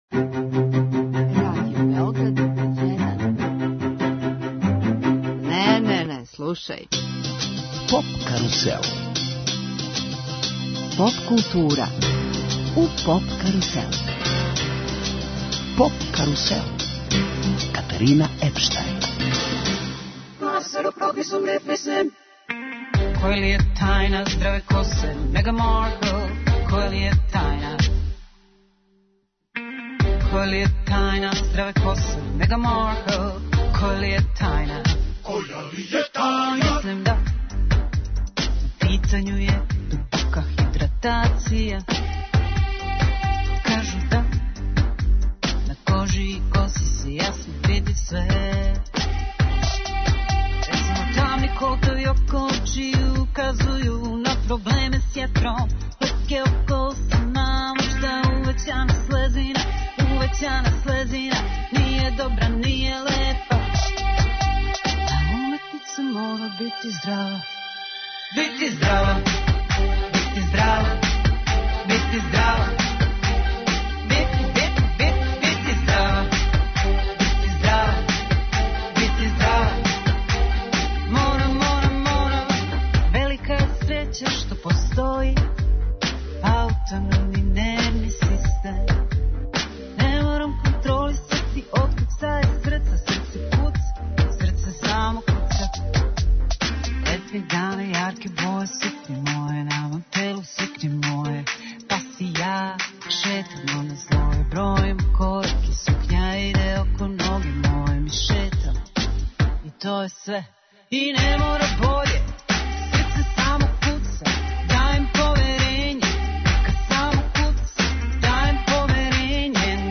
Емисија 'Поп карусел' је посвећена Евросонгу а музиком подсећамо на победнике, највеће хитове и домаће представнике, највећег европског музичког такмичења.
Гошћа нам је представница Србије на Песми Евровизије, Ана Ђурић Констракта.